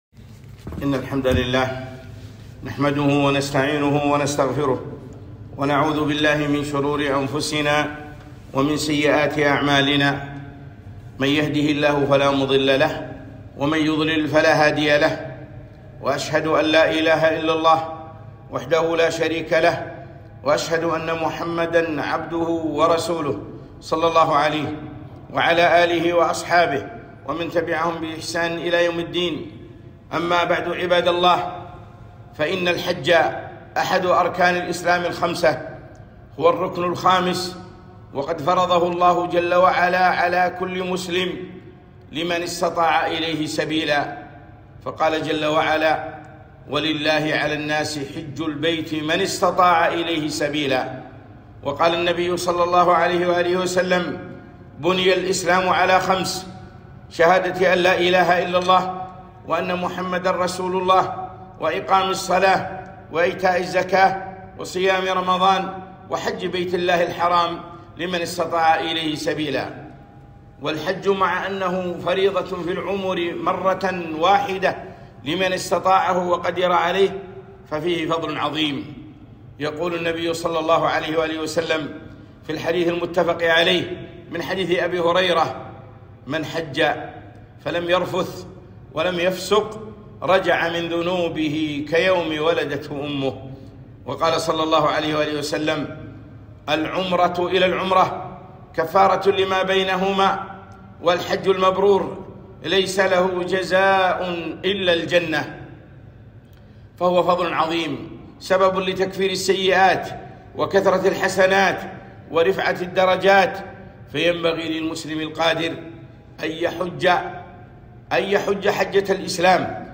خطبة - صفة الحج والالتزام بالتعليمات